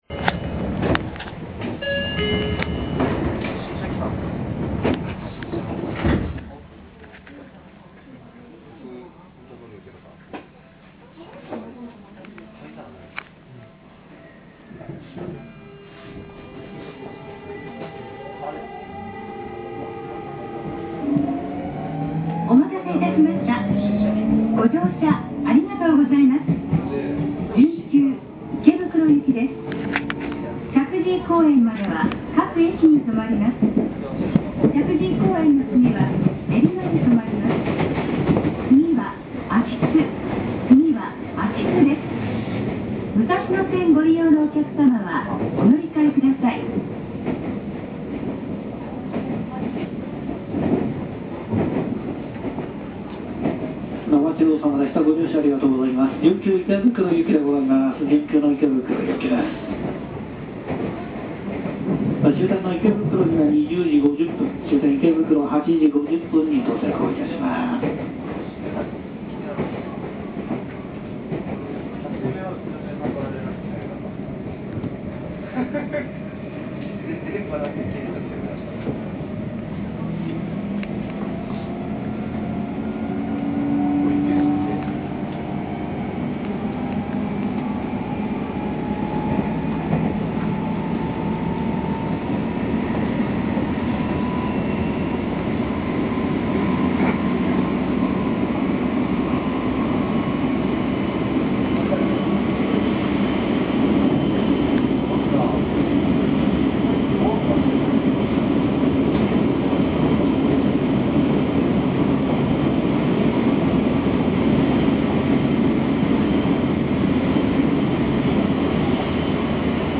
音声3　6000系の走行音